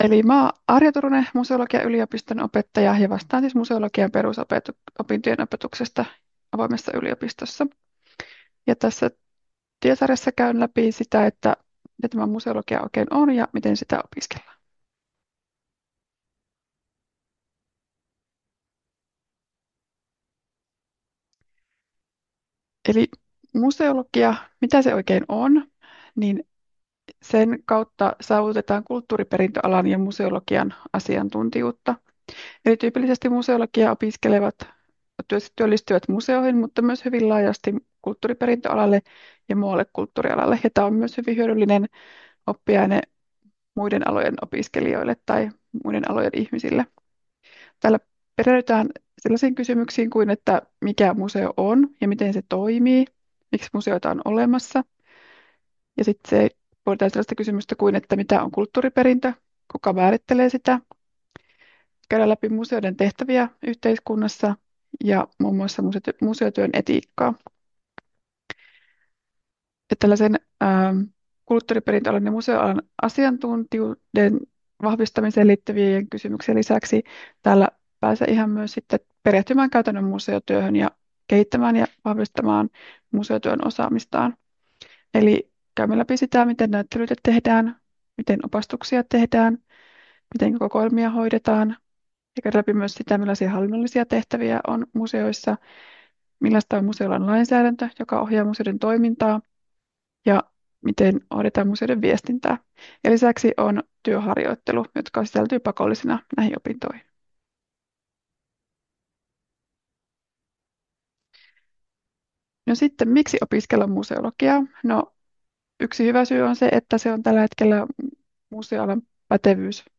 5.9.2024 pidetty webinaari.